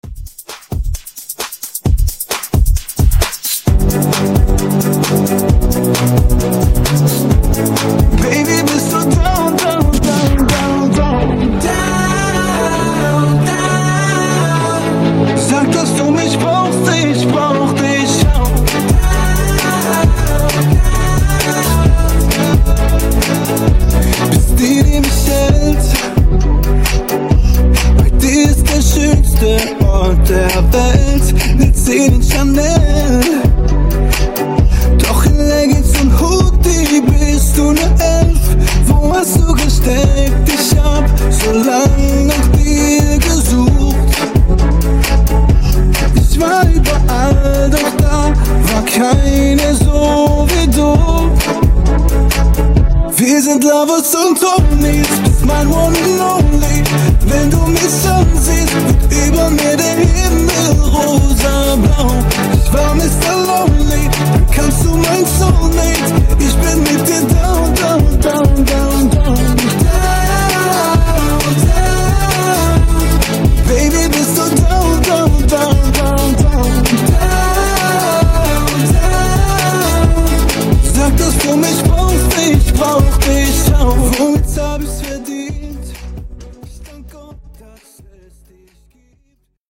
Genres: RE-DRUM , TOP40
Clean BPM: 100 Time